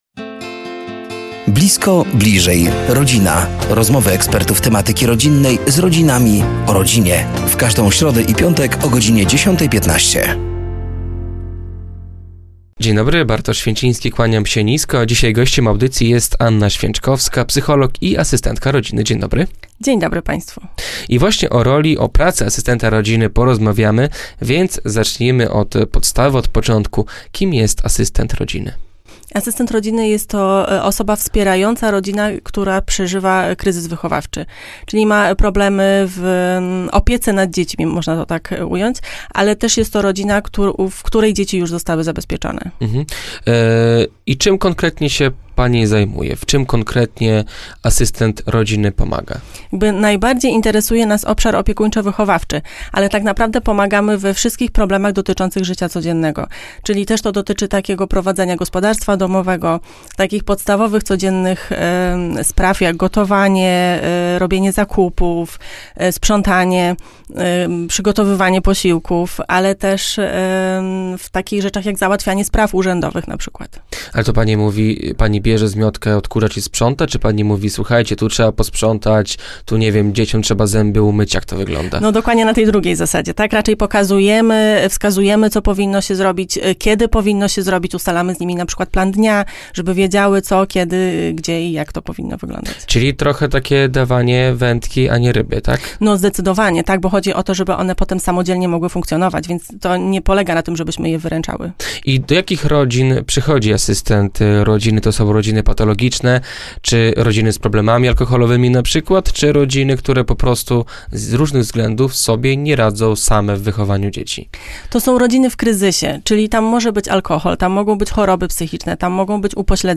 Blisko. Bliżej. Rodzina! To cykl audycji na antenie Radia Nadzieja. Do studia zaproszeni są eksperci w temacie rodziny i rodzicielstwa.